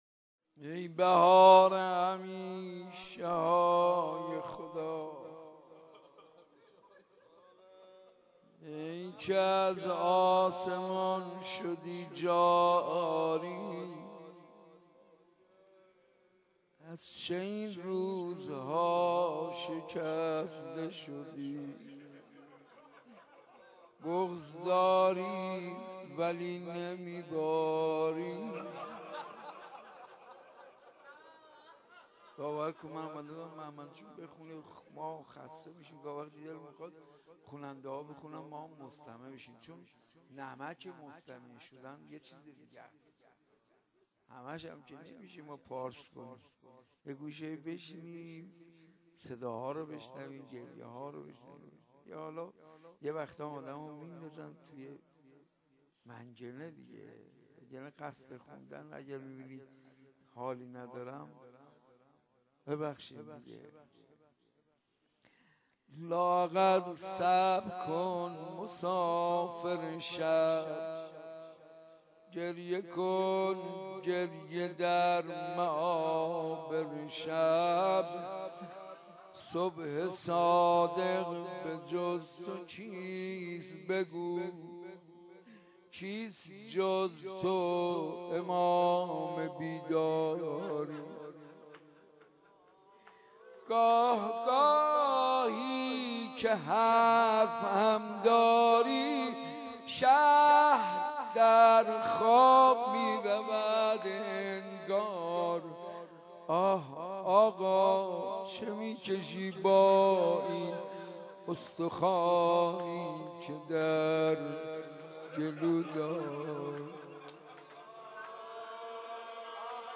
روضه شهادت امام صادق(ع) - ای بهار همیشه های خدا - حاج منصور ارضی.mp3